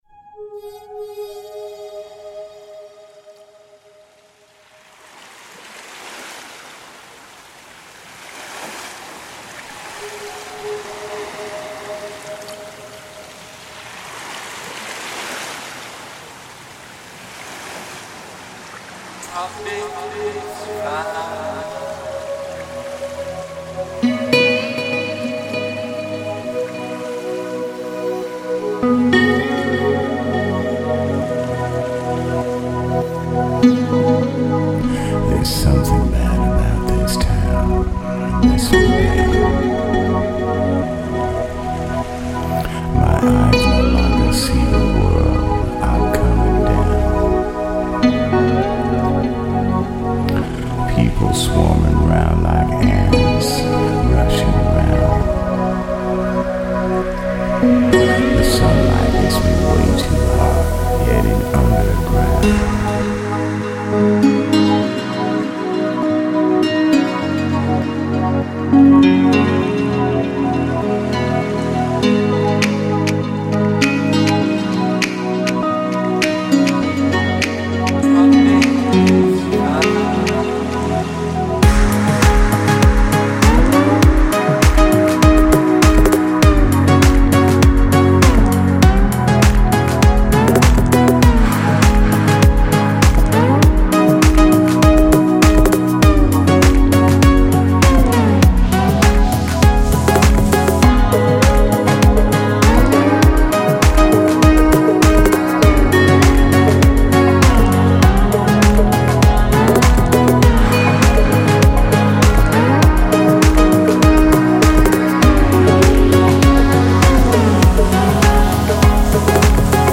Žánr: Electro/Dance
Tropical and chill sound for relax.